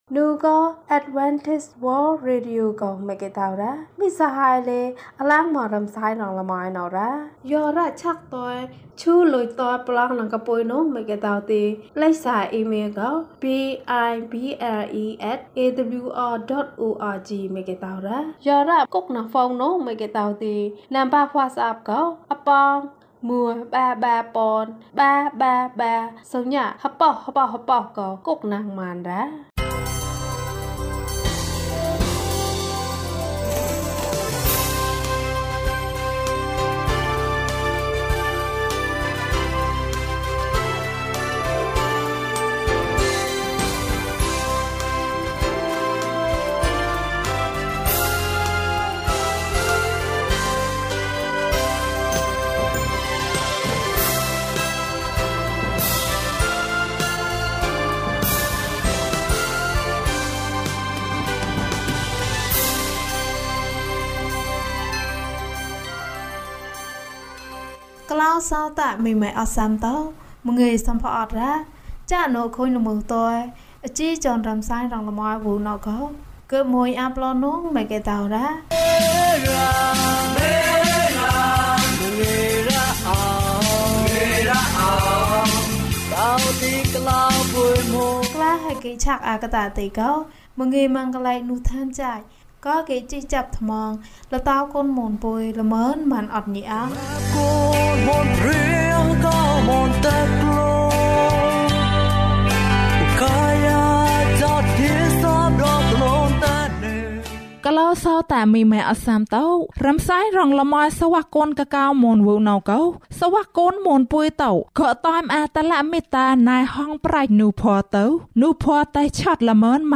သူ့အပြုံးကို ငါမြင်တယ်။ ကျန်းမာခြင်းအကြောင်းအရာ။ ဓမ္မသီချင်း။ တရားဒေသနာ။